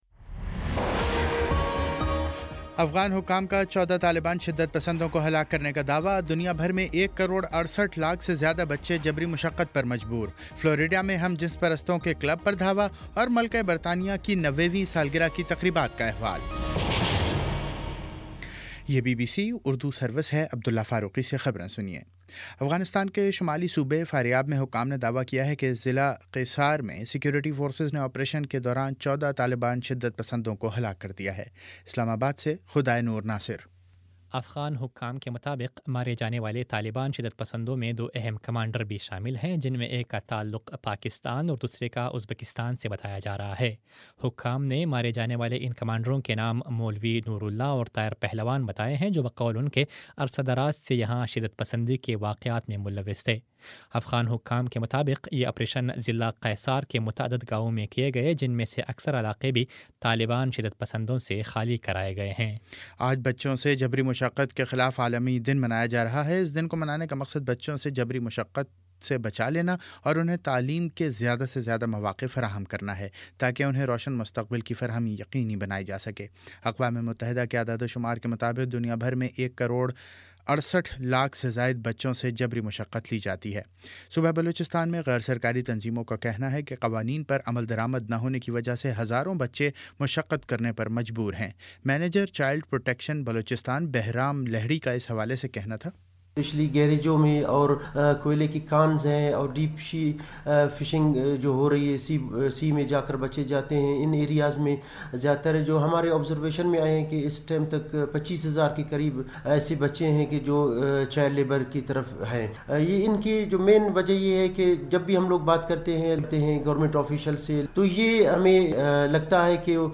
جون 12 : شام پانچ بجے کا نیوز بُلیٹن